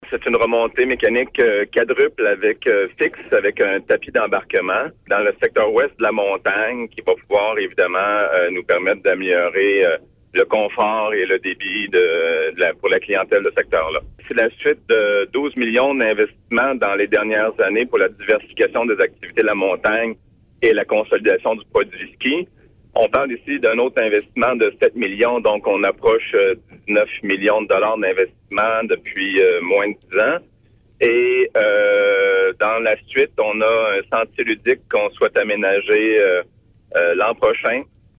Dans une entrevue